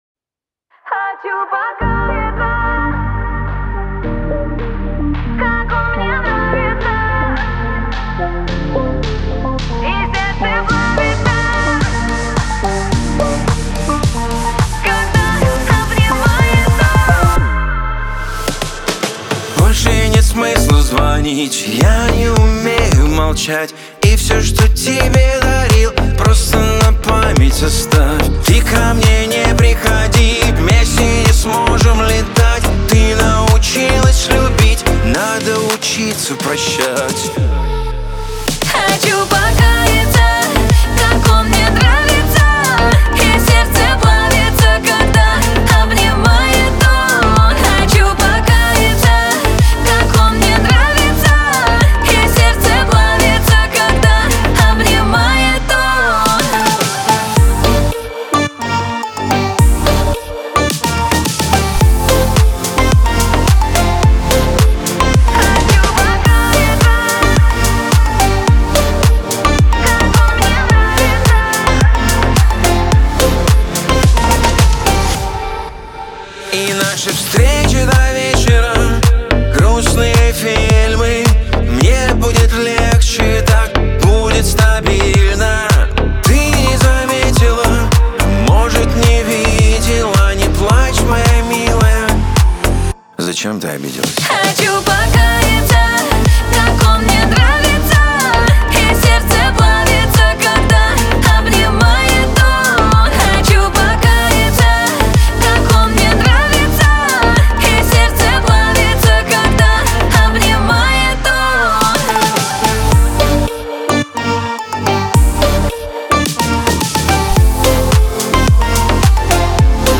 Русские поп песни